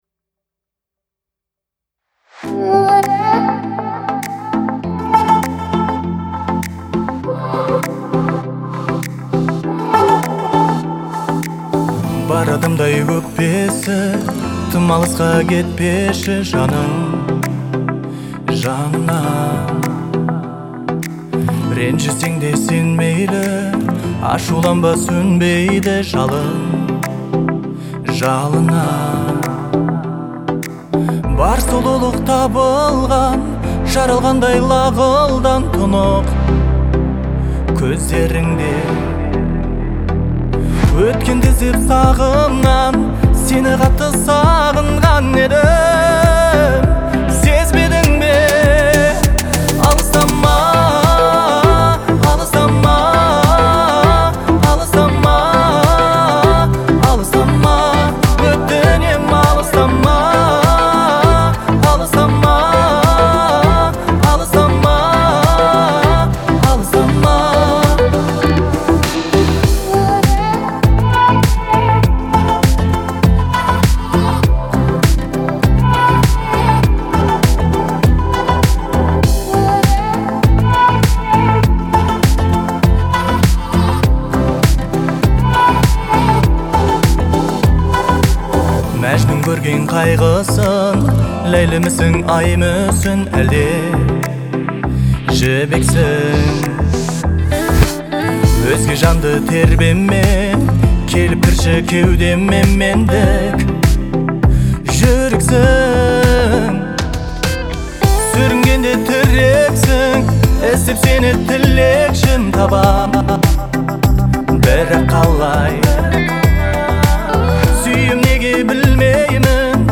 обладая выразительным голосом и мастерством исполнения